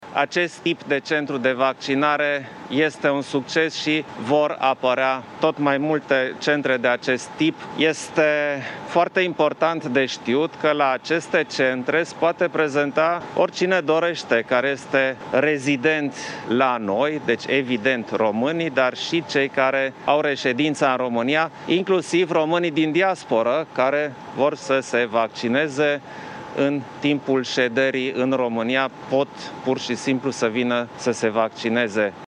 Șeful statului a vizitat astăzi centrul din fața Casei Poporului, unde în prima zi de funcționare, câteva sute de persoane au primit deja prima doză: